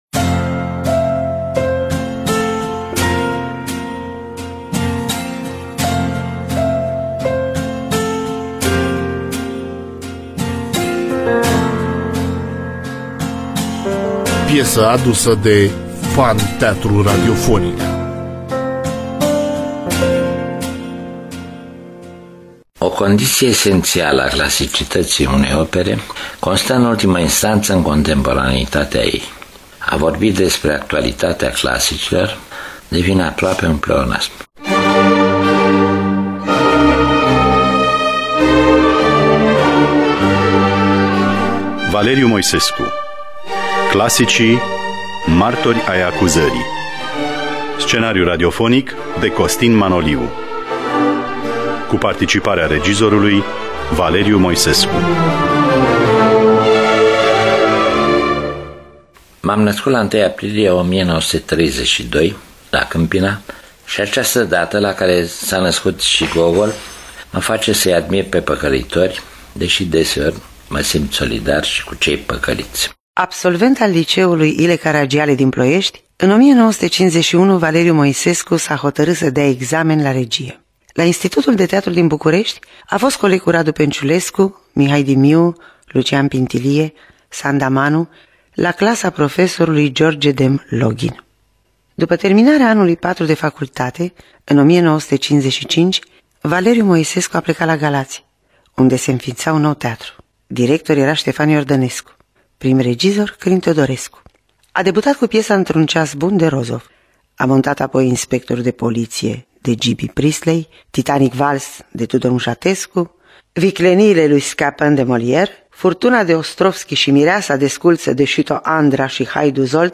Scenariu radiofonic
Cu participarea extraordinară a regizorului Valeriu Moisescu.